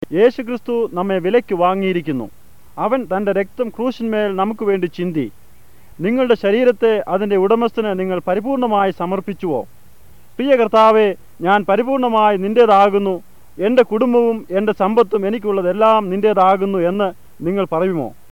It actually sounds a bit like French near the end, maybe some influence in it?